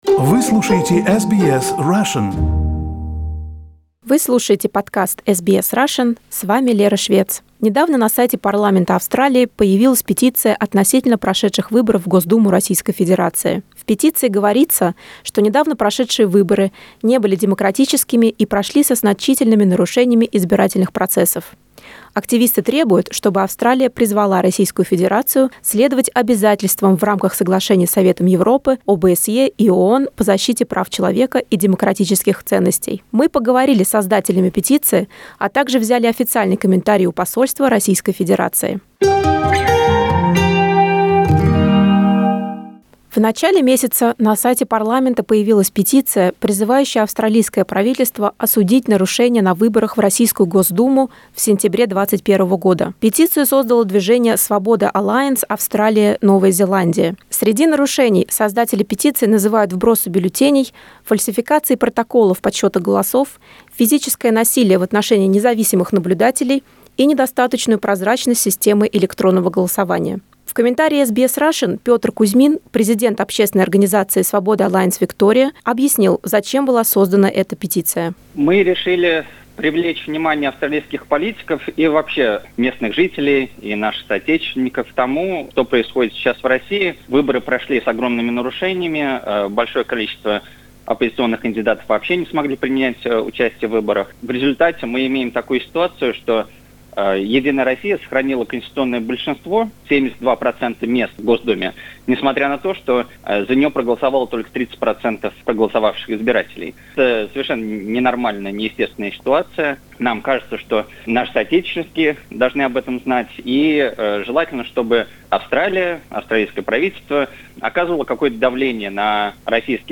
A petition regarding the recent State Duma elections in Russia has been published on the website of the Australian Parliament. The petition claims that the recent elections were held with significant violations of the electoral processes. SBS Russian spoke to the authors of the petition, and also took an official comment from a representative of the Russian Embassy.